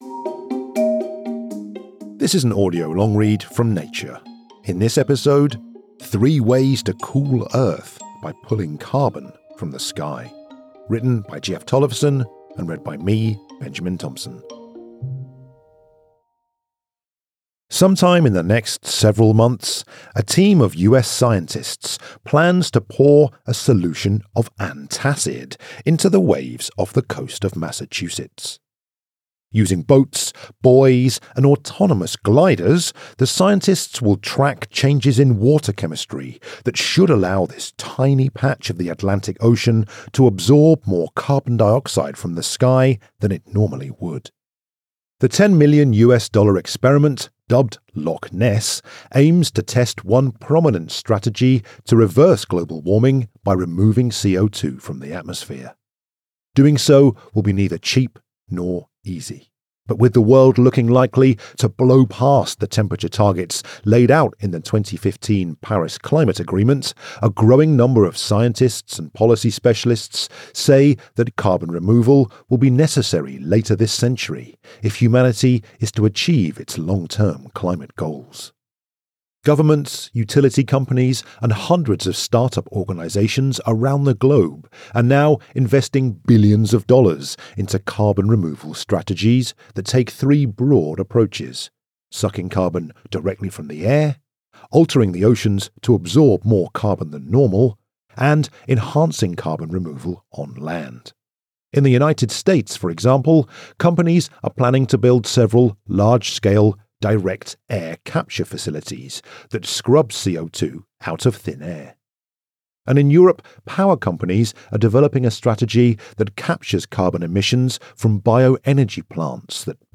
Ses Uzun Okuma: Gökyüzünden Karbon Çekerek Dünyayı Soğumanın Üç Yolu